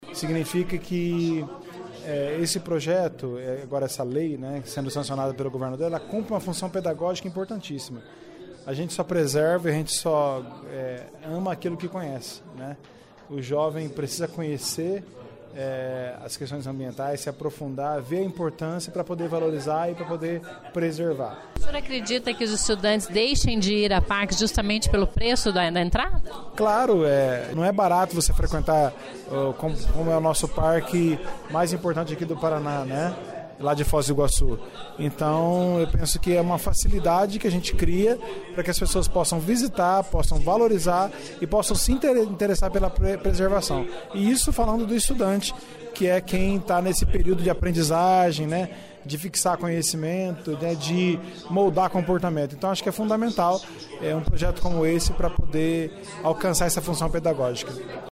Proposta é do deputado Evandro Araújo (PSC) e amplia o benefício da meia-entrada de estudantes para parques como o Nacional do Iguaçu.  Para o deputado, é uma forma pedagógica de conscientar os jovens para a preservação ambiental. Ouça a entrevista.